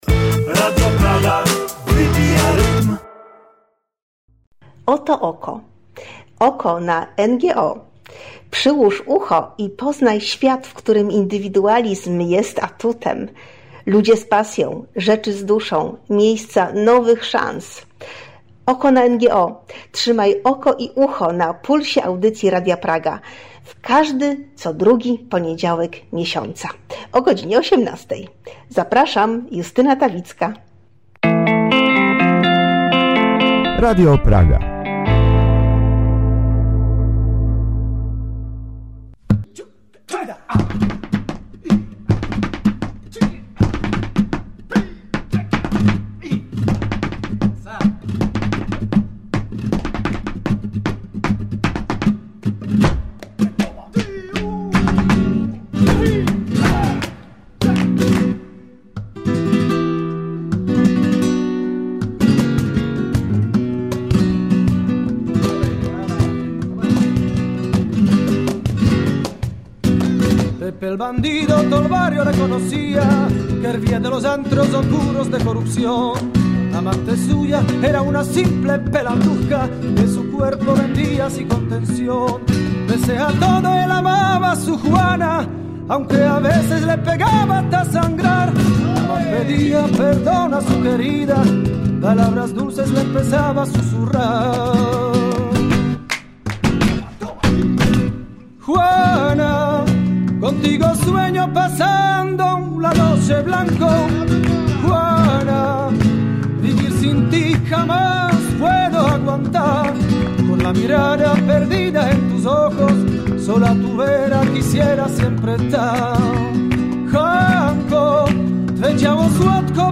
Posłuchajcie pełnej pasji rozmowy o zapachu jaśminu i pomarańczy.